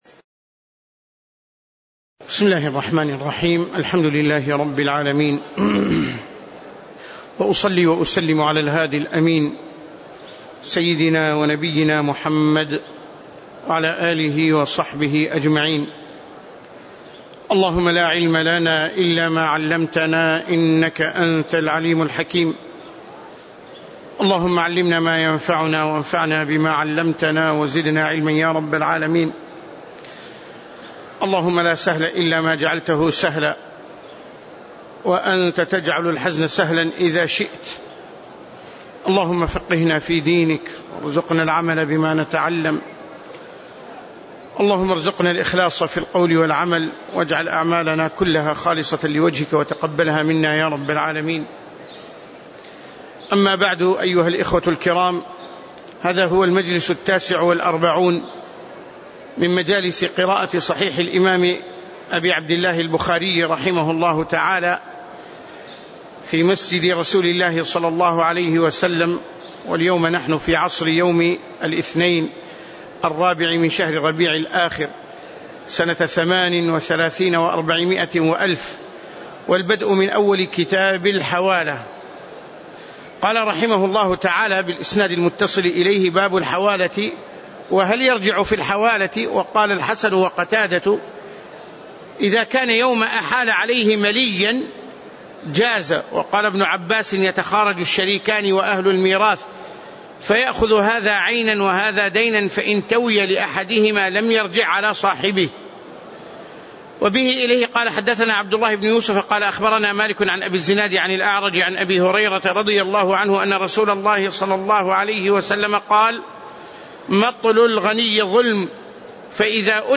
تاريخ النشر ٤ ربيع الثاني ١٤٣٨ هـ المكان: المسجد النبوي الشيخ